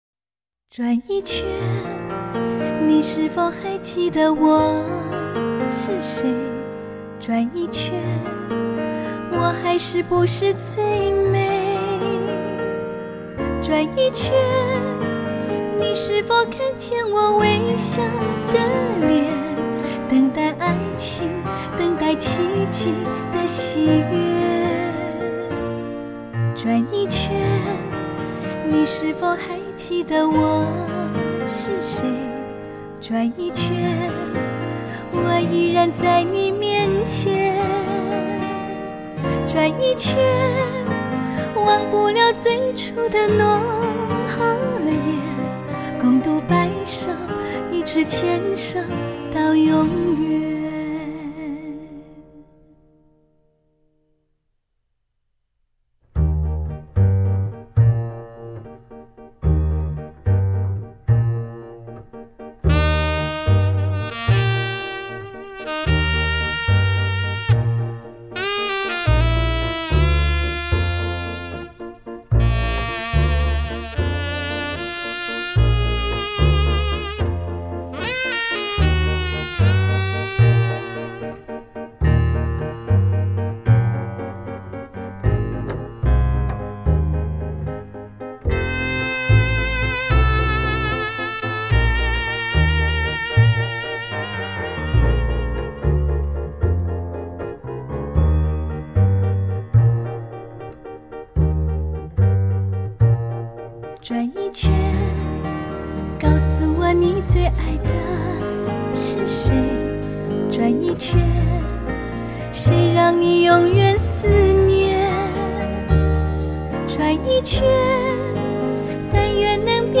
融合古典音乐与流行抒情传递人类生命起源的妈妈情歌
巧夺天工、木结他、钢琴、低音大提琴、笛子、昔士风及口琴等“清一色”acoustic乐器精彩演奏，乐声立体鲜明，结像传神。